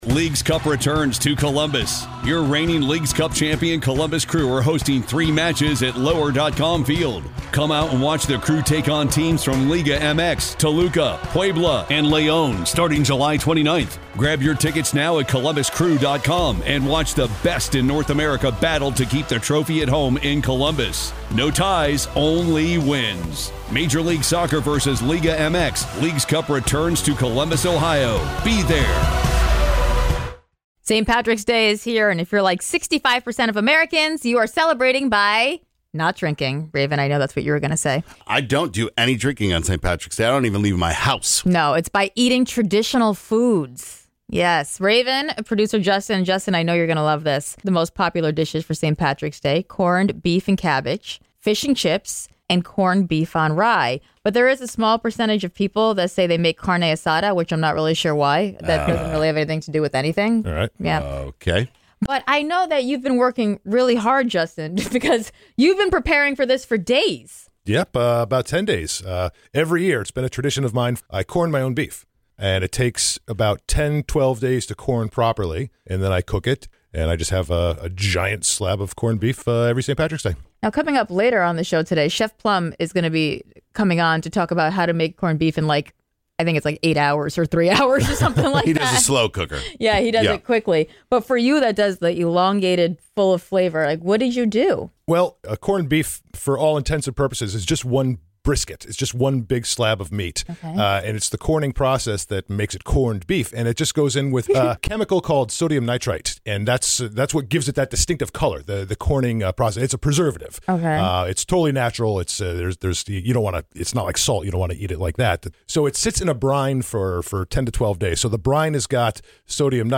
The Girl Scouts are in the studio!